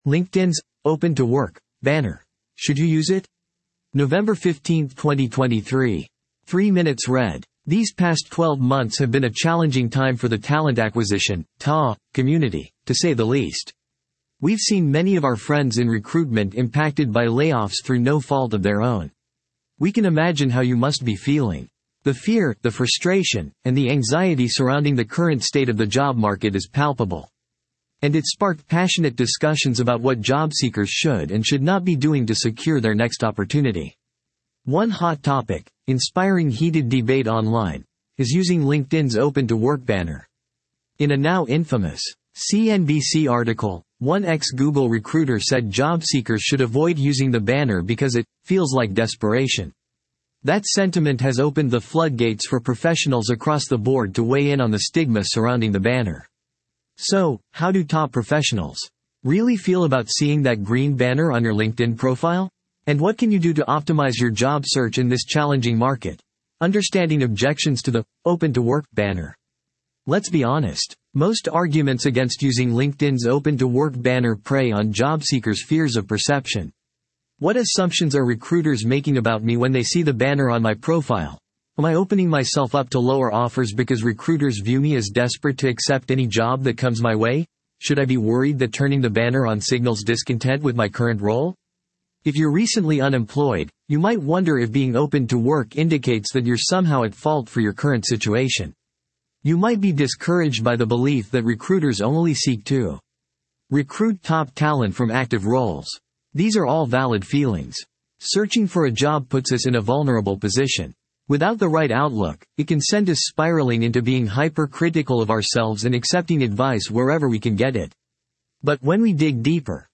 You can use this audio player to convert website page content into human-like speech. 11:11 00:00 / 14:00 1.0X 2.0X 1.75X 1.5X 1.25X 1.0X 0.75X 0.5X These past 12 months have been a challenging time for the talent acquisition (TA) community, to say the least.